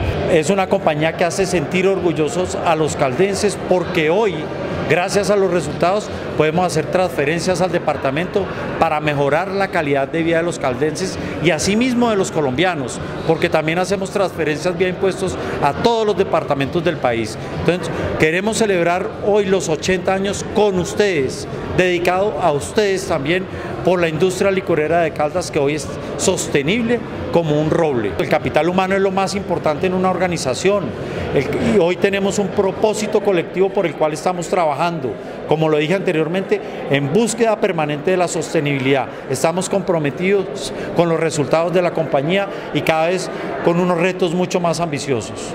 El pasado jueves se llevó a cabo en la Gobernación de Caldas un acto de conmemoración de las bodas de roble (80 años) de la empresa más importante del departamento.